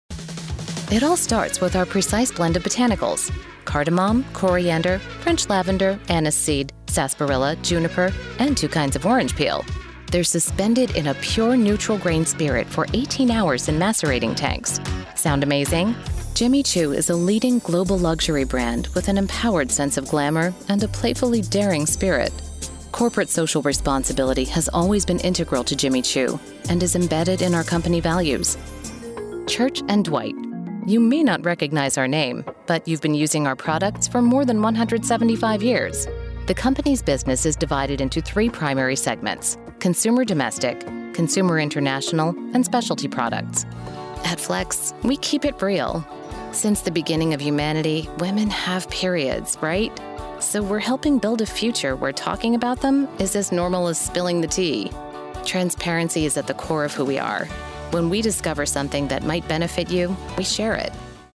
Female
Yng Adult (18-29), Adult (30-50)
Corporate Narration
Words that describe my voice are Conversational, Sophisticated, Real.
All our voice actors have professional broadcast quality recording studios.